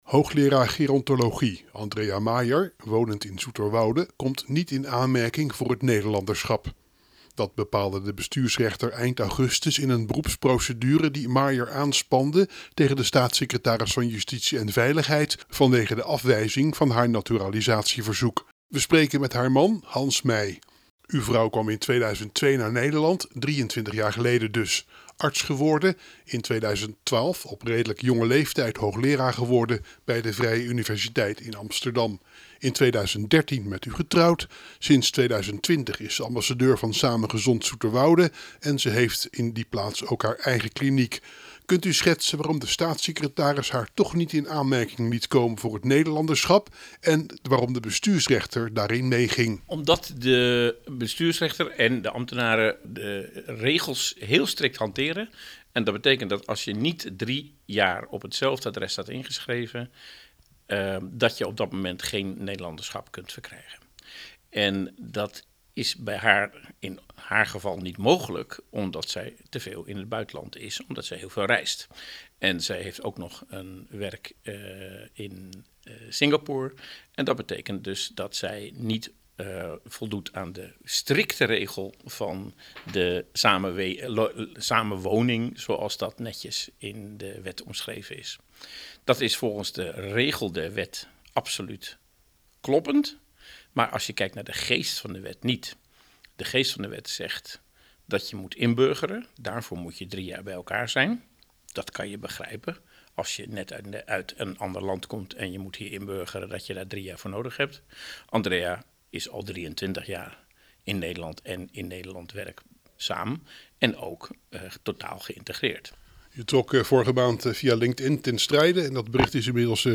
AUDIO: Verslaggever